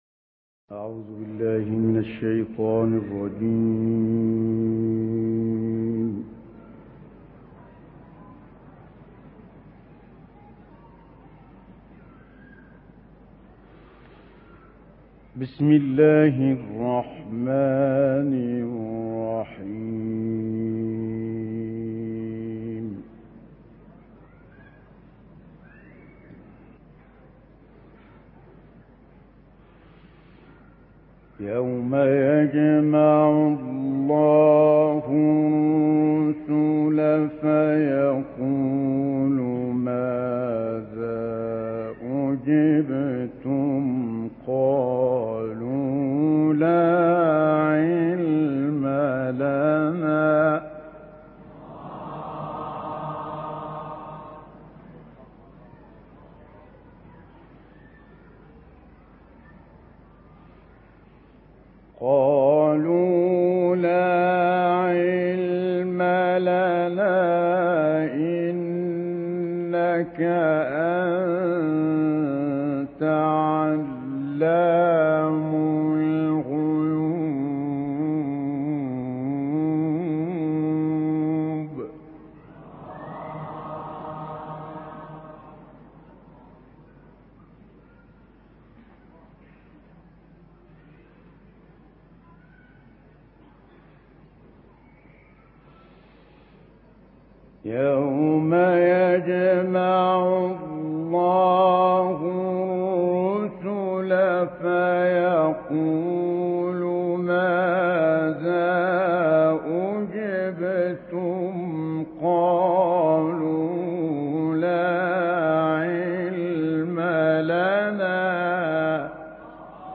تلاوت آیاتی از سوره مائده توسط استاد متولی عبدالعال
قاری مصری